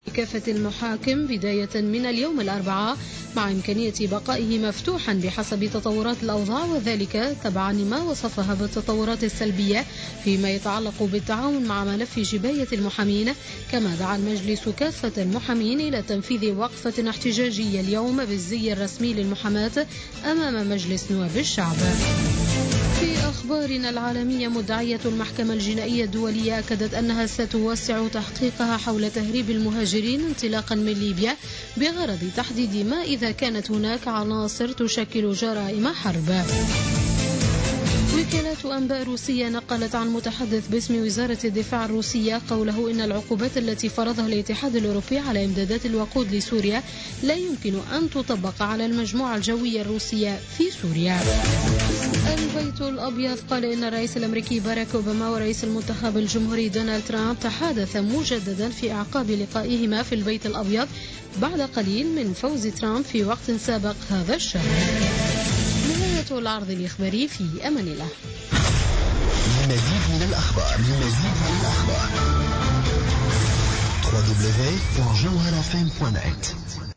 نشرة أخبار منتصف الليل ليوم الاربعاء 23 نوفمبر 2016